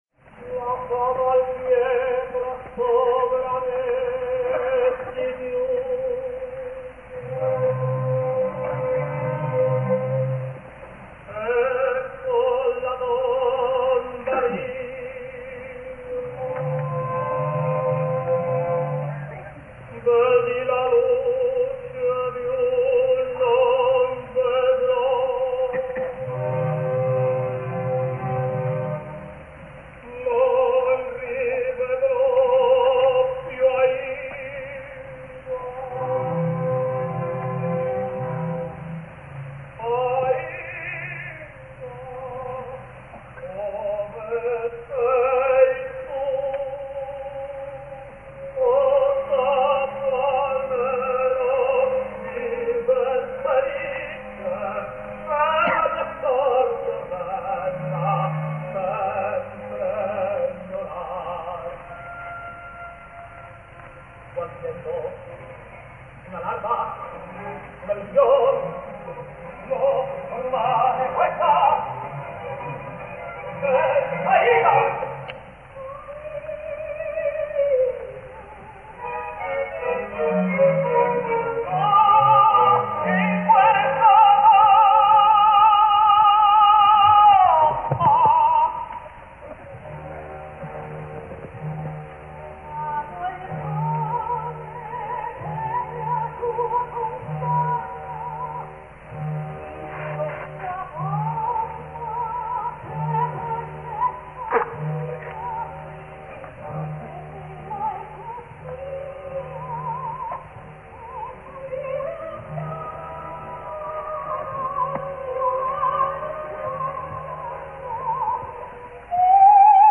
O terra, addio, with Licia Albanese